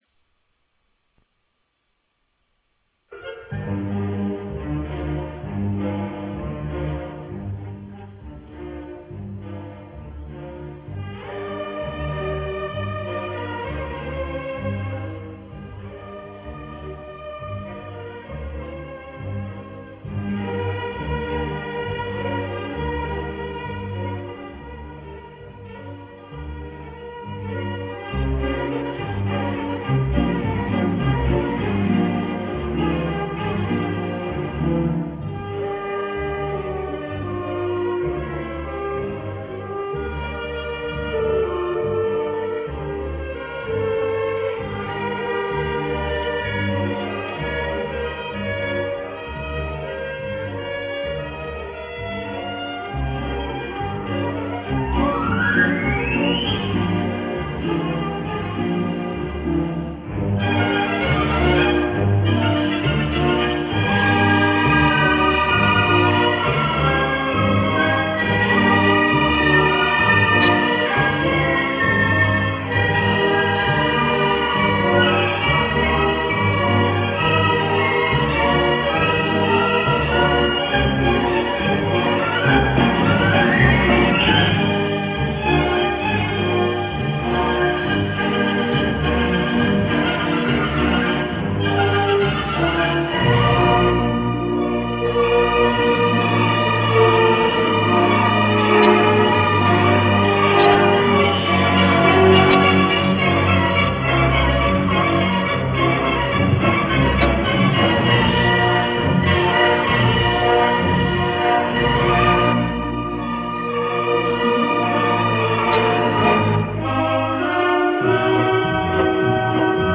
deuxième ballet du compositeur